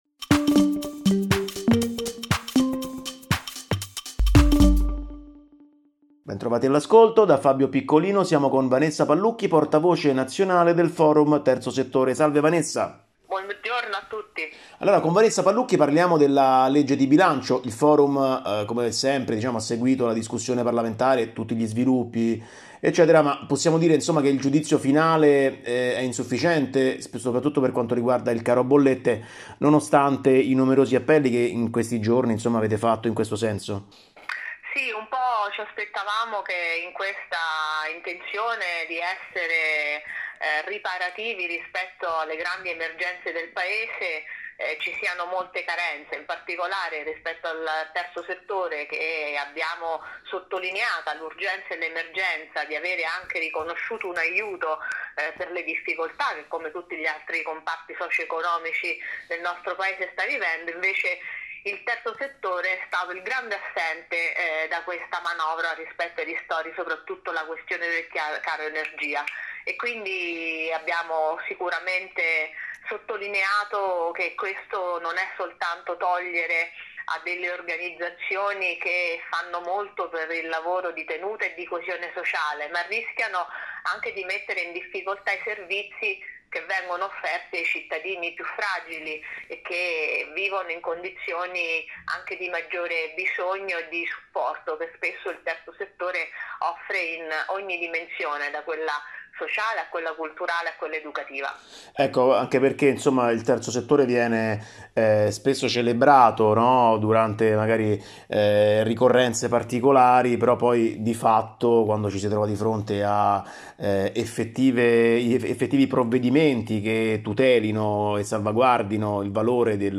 Manovra e non-profit: una questione di “cecità politica”. Intervista